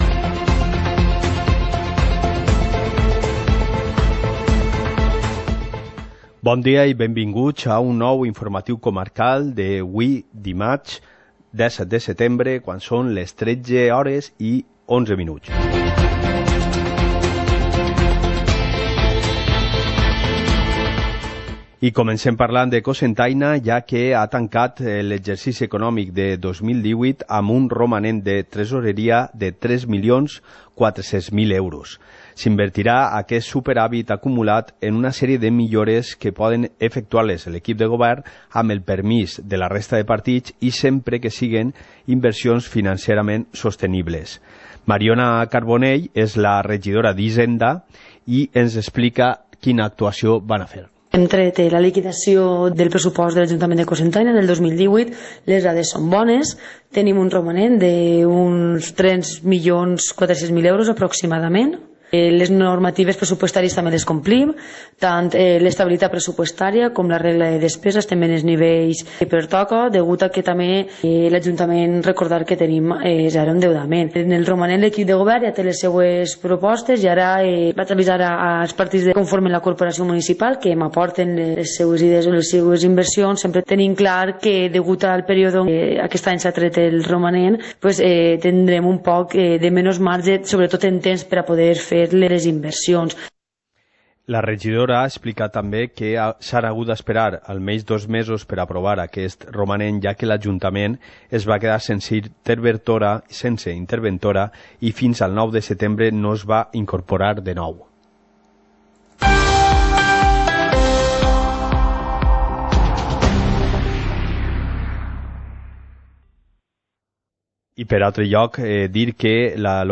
Informativo comarcal - martes, 17 de septiembre de 2019